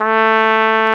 BRS CORNET01.wav